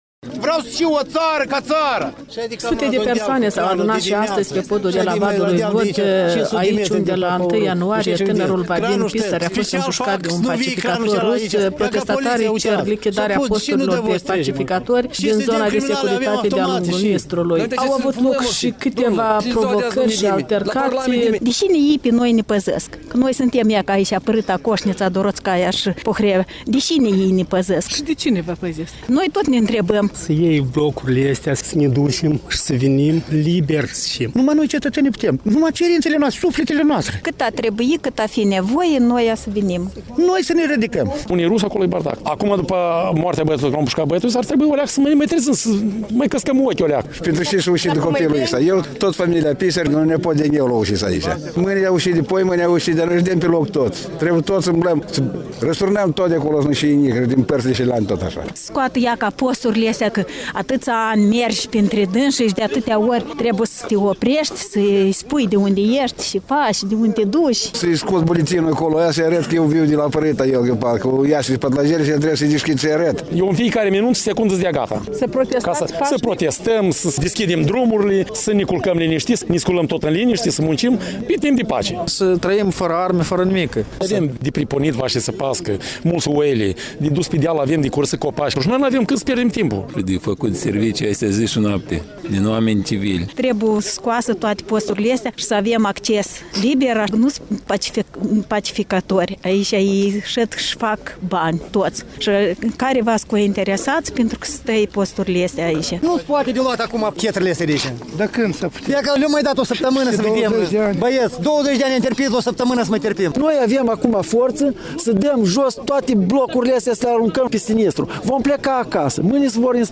Un reportaj de la protestul din Vadul lui Vodă